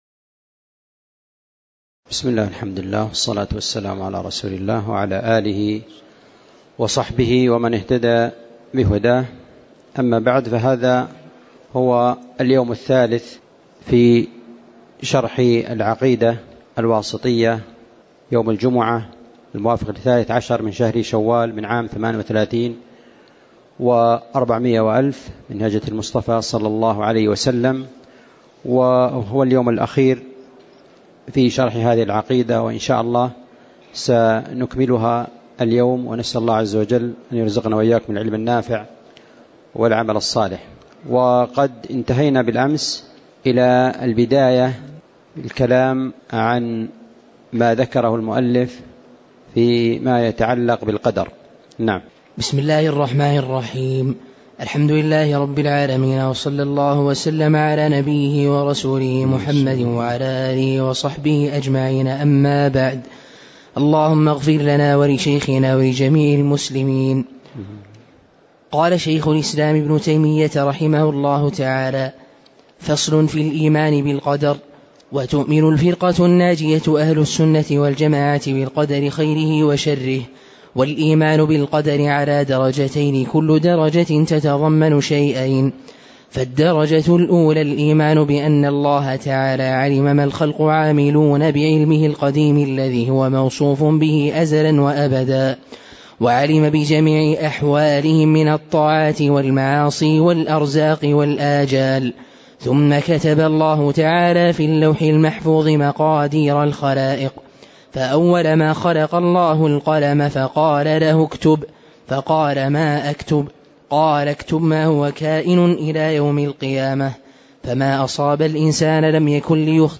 تاريخ النشر ١٣ شوال ١٤٣٨ هـ المكان: المسجد النبوي الشيخ